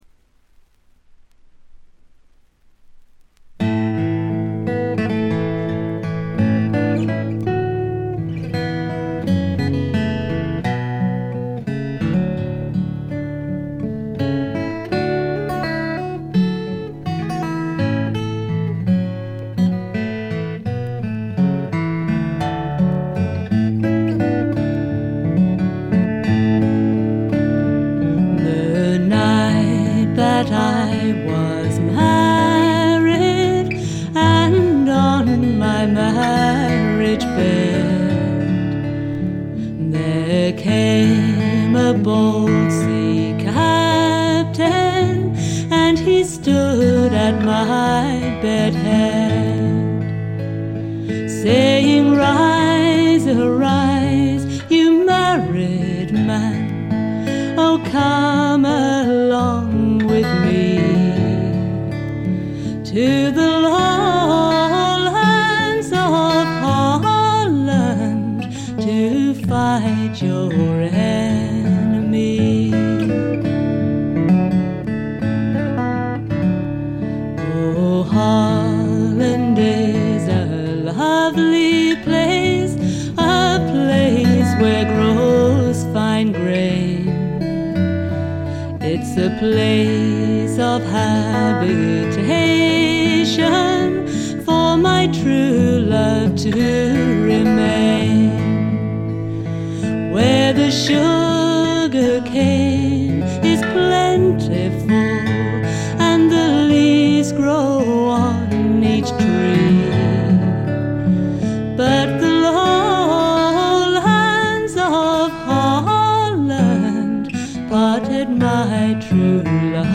トラッド推薦盤。
試聴曲は現品からの取り込み音源です。
Vocals
Guitar, Flute